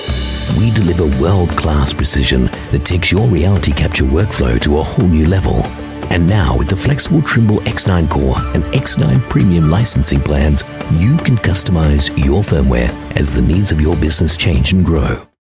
Corporate Videos
Neumann TLM 103 mic